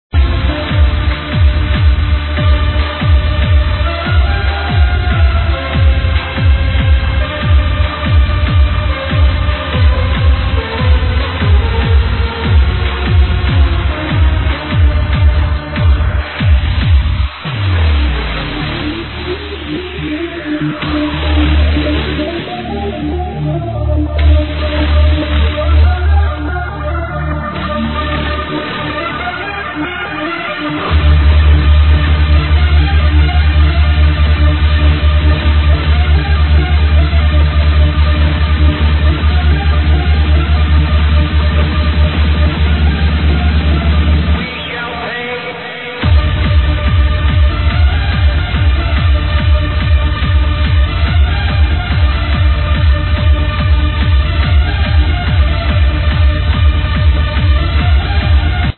Question Please help ID this hard trance track
on the Australian radio station